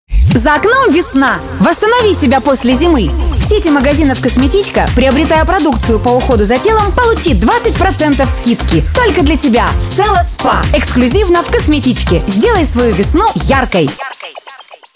Professionell female voice over artist from Russia.
russische Sprecherin
Sprechprobe: Industrie (Muttersprache):
female russian voice over talent